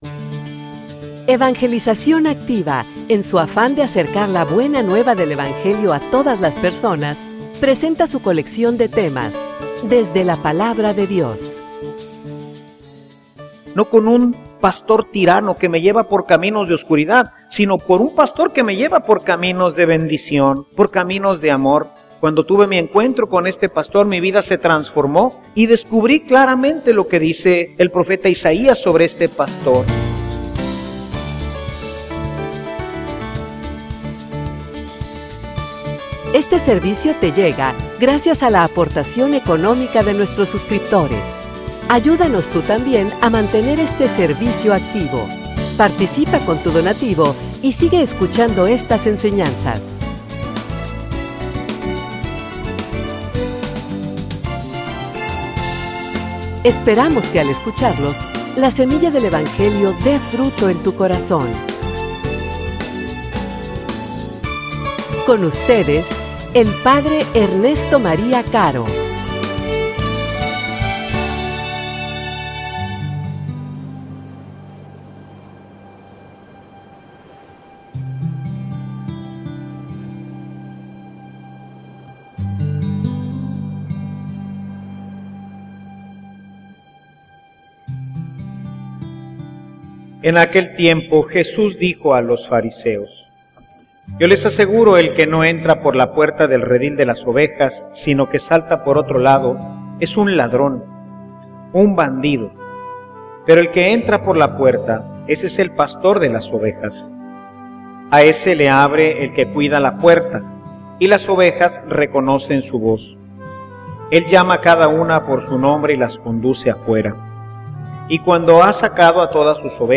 homilia_Encuentro_con_el_buen_pastor.mp3